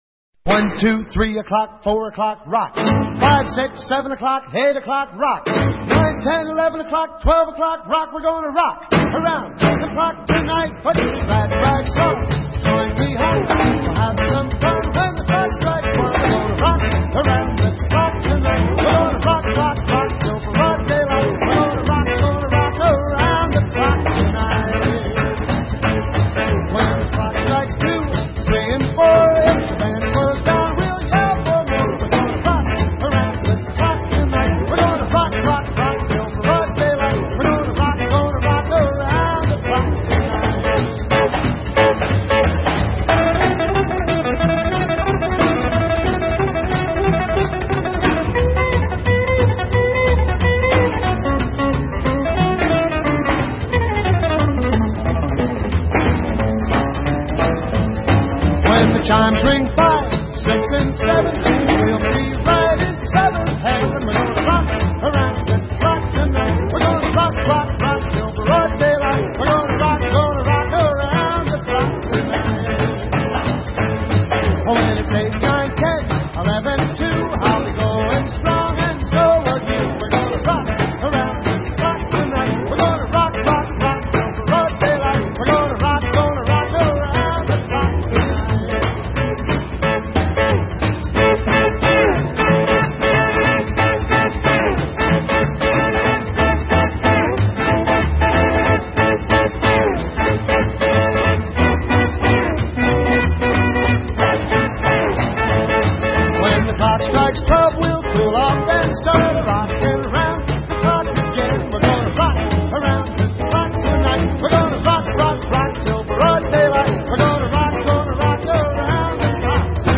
своеобразной визитной карточки рок-н-ролла.